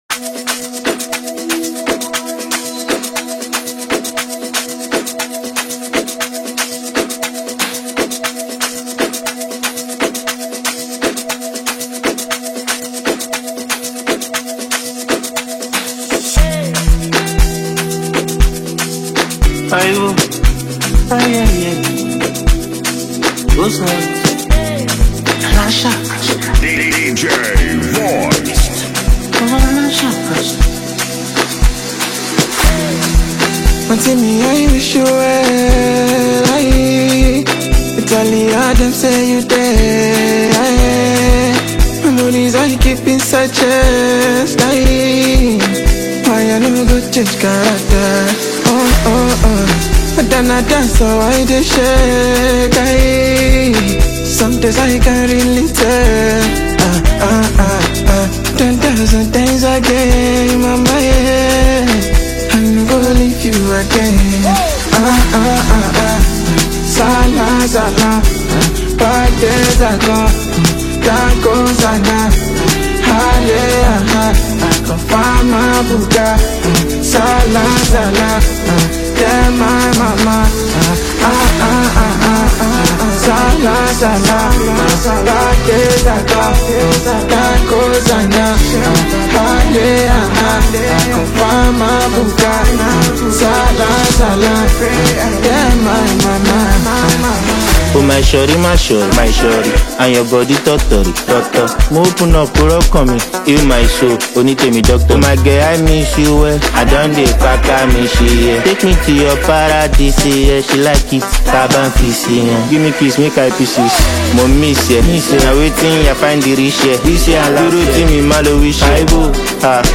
energetic new single
With its pulsating beat and collaborative synergy